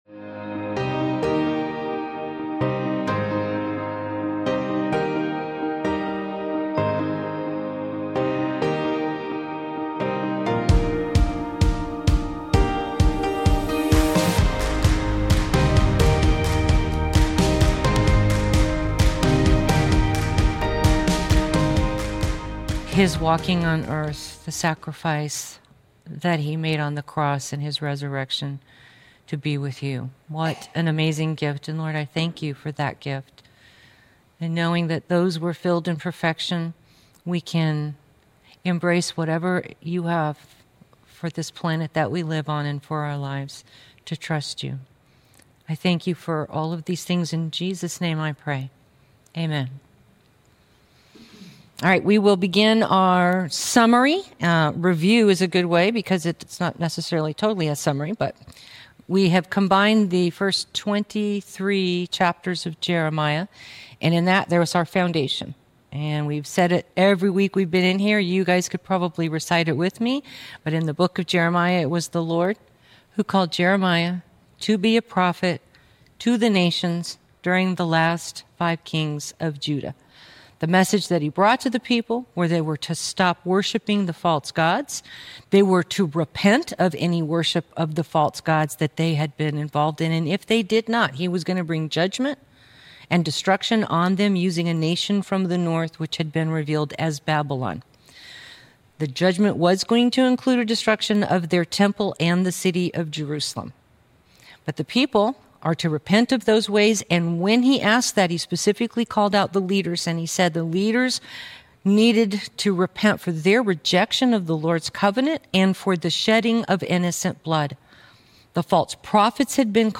Jeremiah - Lesson 47-48 | Verse By Verse Ministry International